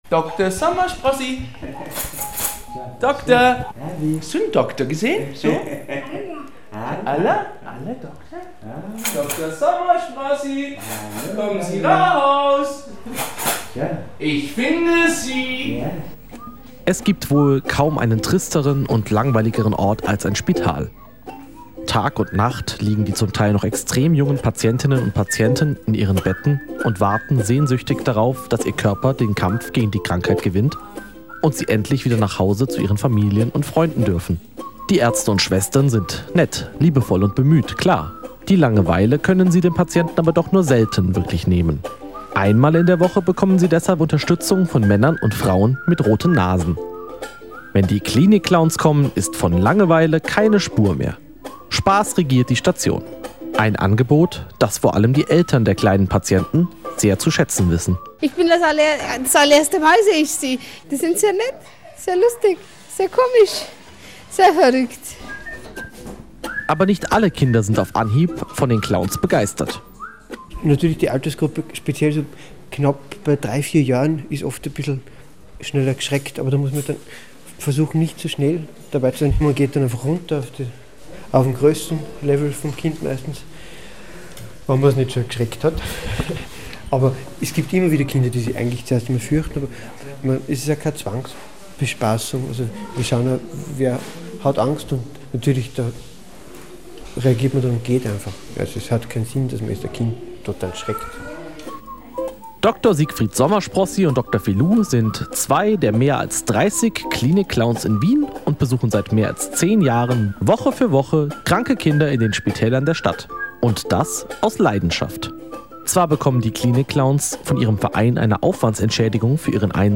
Wir haben die beiden Spaß-Doktoren einen Nachmittag lang bei ihrer Visite auf der Kinderstation begleitet und mit ihnen über die schönen und weniger schönen Seiten als Clowndoktor gesprochen.